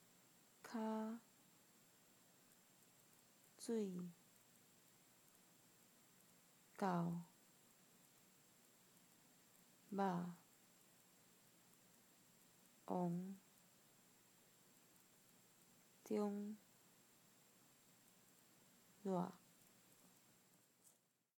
POJ_tones.ogg.mp3